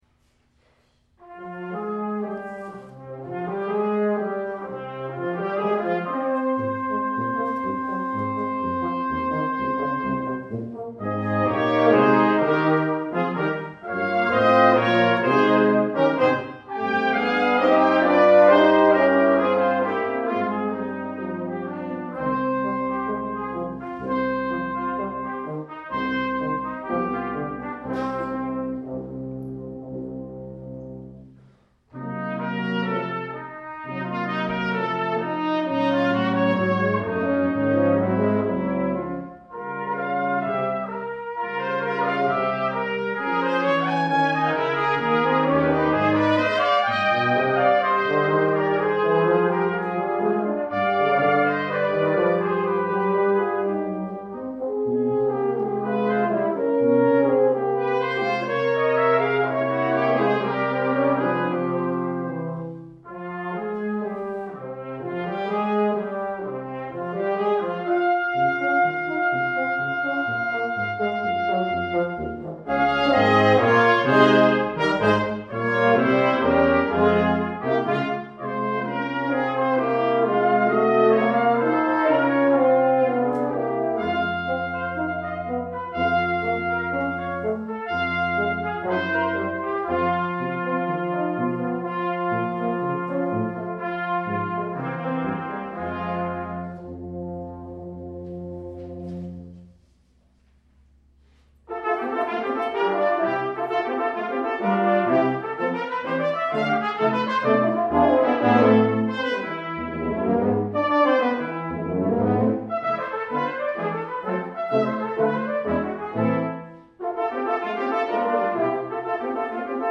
Trumpet
Horn
Euphonium
Tuba
December 2009 Recital: